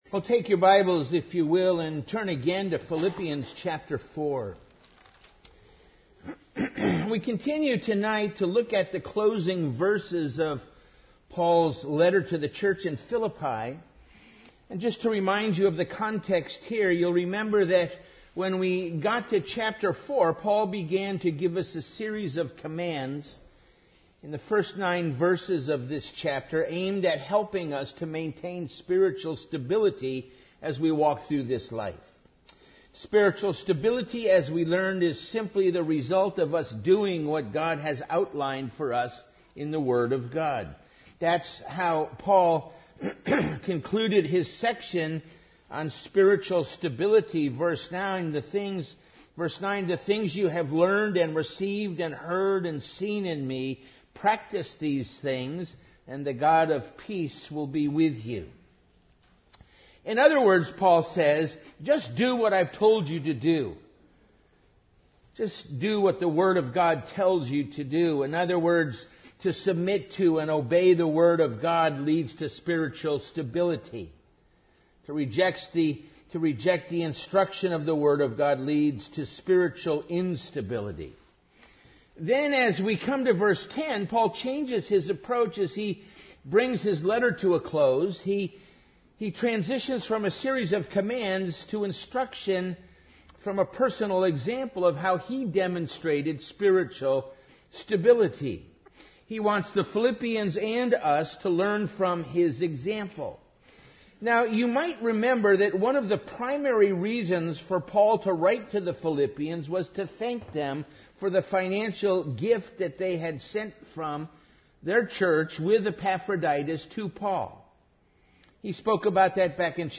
Series: Evening Worship